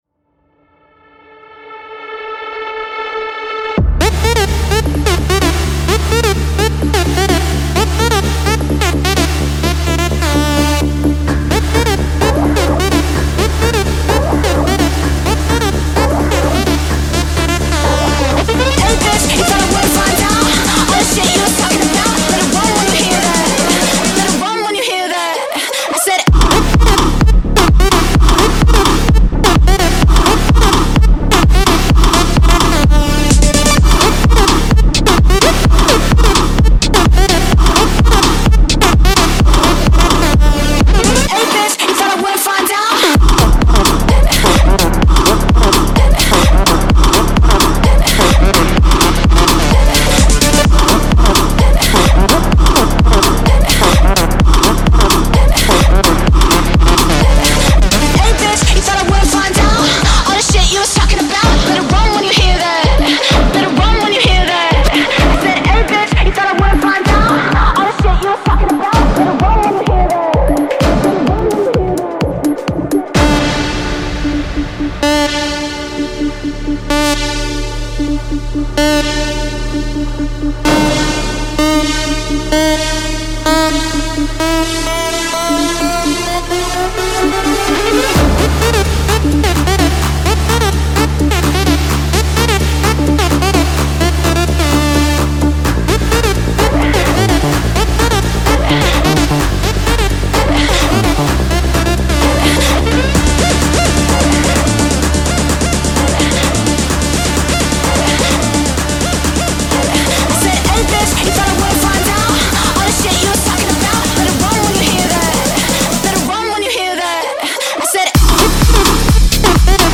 • Жанр: EDM, Dance